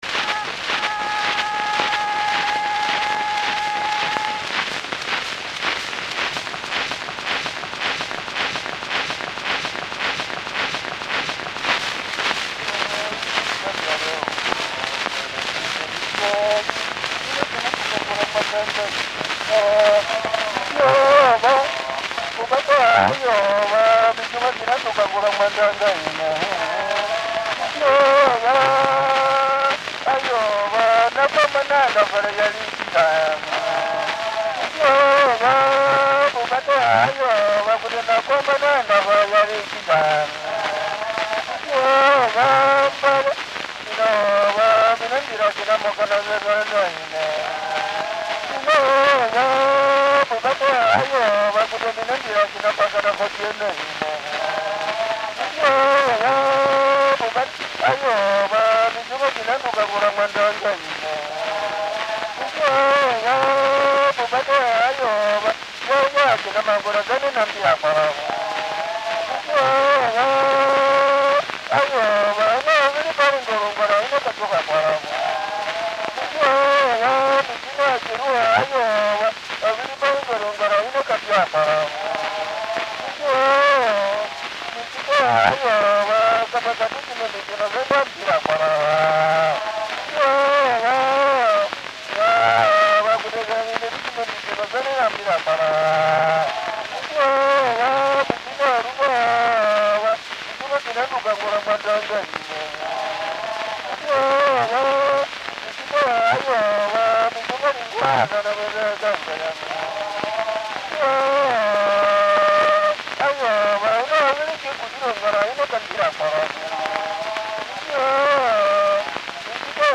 Zande war song and chorus
From the sound collections of the Pitt Rivers Museum, University of Oxford, being from a collection of wax cylinder recordings of Zande songs, dances and spoken language made by social anthropologist Edward Evans-Pritchard in South Sudan between 1928 and 1930.
Recorded by Edward Evan Evans-Pritchard.